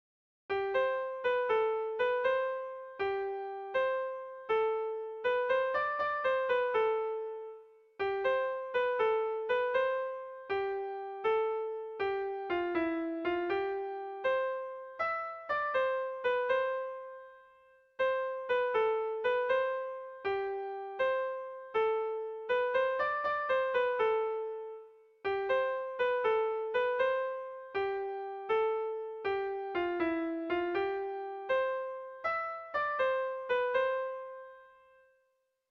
Irrizkoa
Moxkor kantua deitzen dio Gure Herriak.
Laukoa, txikiaren moldekoa, 2 puntuz (hg) / Bi puntukoa, txikiaren moldekoa (ip)
AB